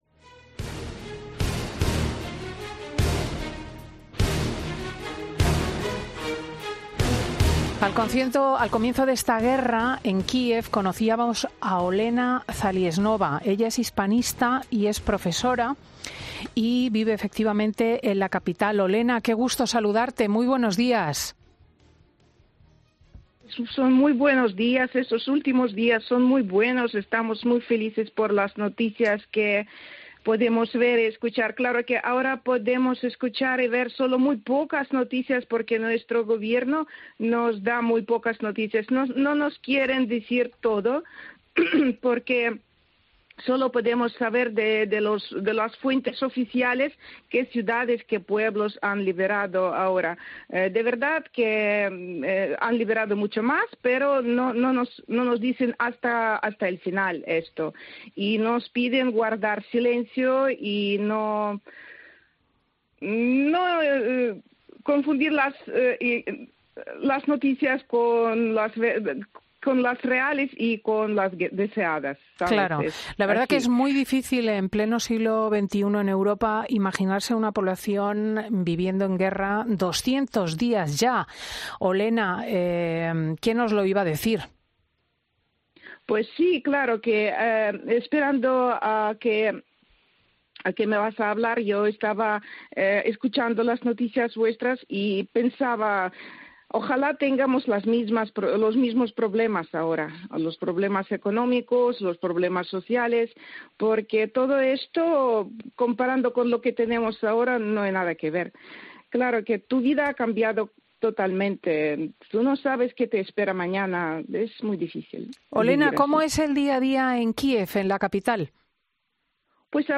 En Fin de Semana de COPE hemos hablado con dos ucranianas de Kiev y Odesa que nos han contado cómo están viviendo esta etapa de la guerra